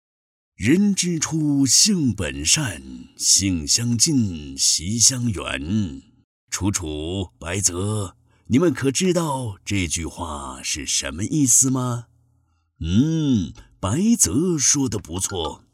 男国172_动画_老人_爷爷教三字经.mp3